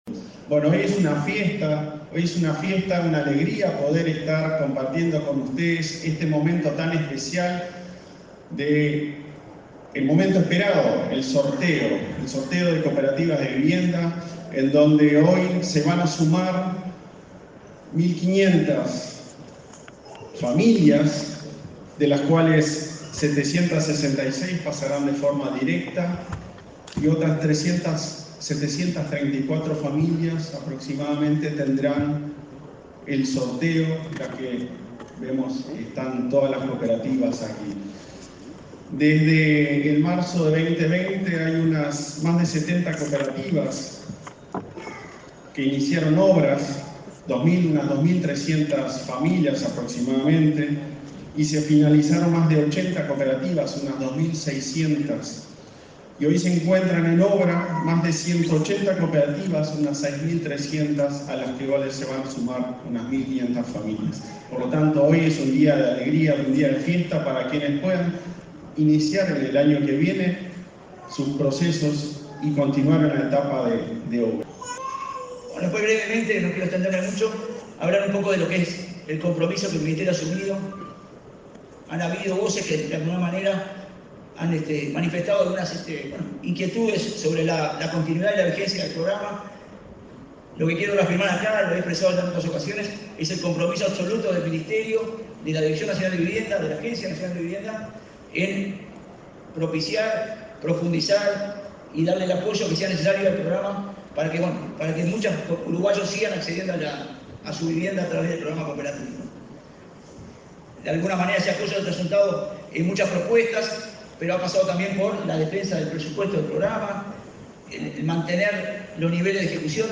Palabras de autoridades de Vivienda
El presidente de la Agencia Nacional de Vivienda, Klaus Mill; el director nacional de Vivienda, Jorge Ceretta; y la ministra Irene Moreira,